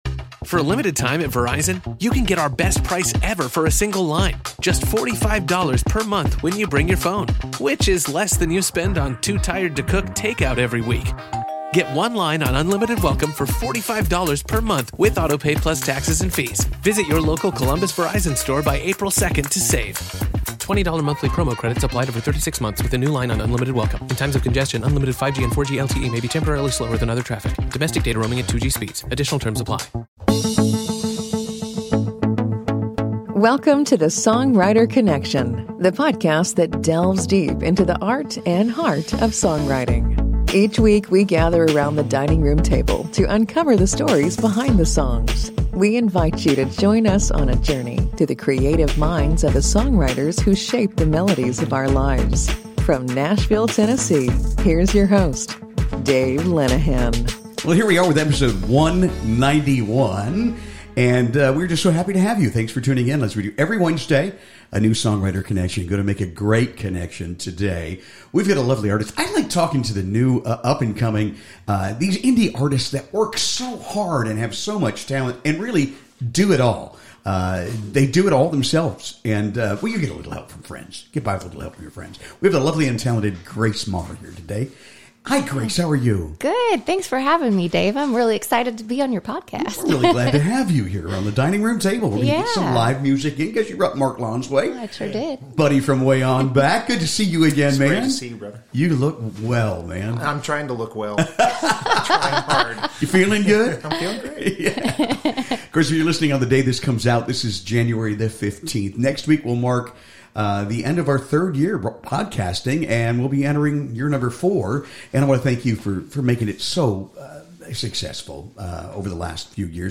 special live performances around the dining room table—raw, intimate, and full of heart